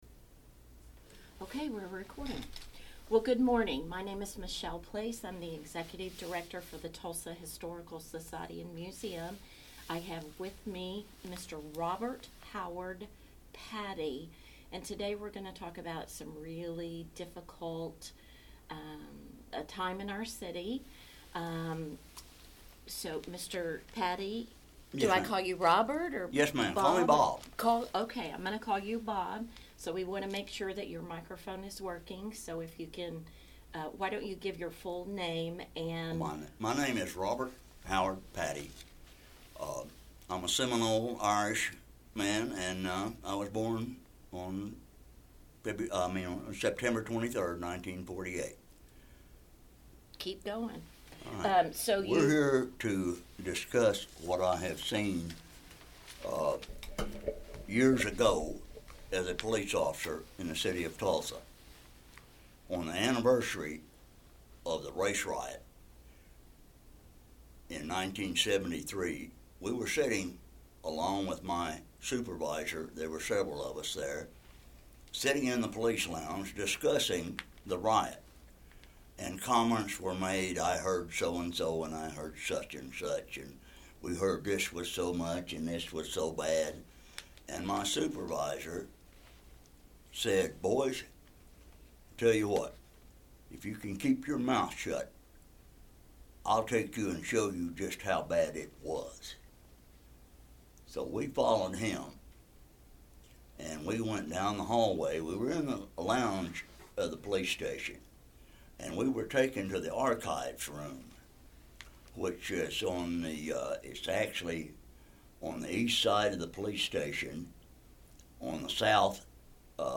Scope & Content Content Warning: This recording contains strong language as well as descriptions of violence and mass burials.